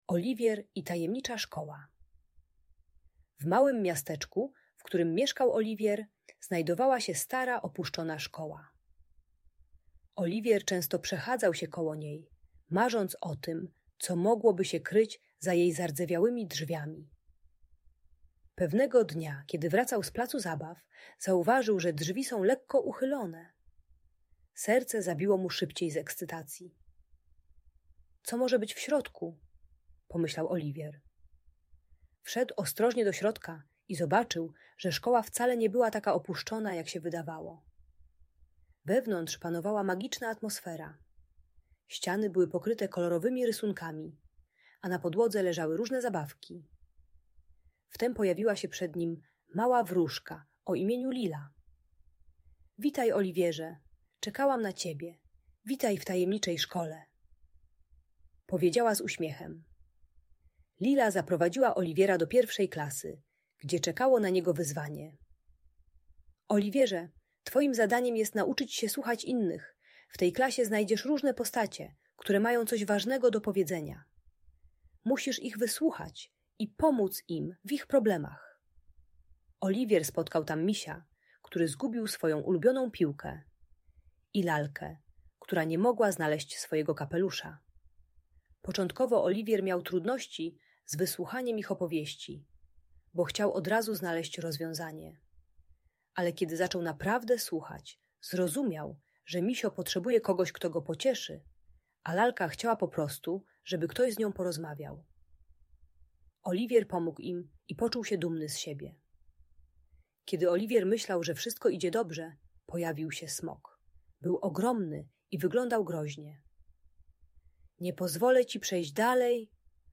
Audiobajka uczy techniki głębokiego oddychania i liczenia do dziesięciu zamiast uderzania.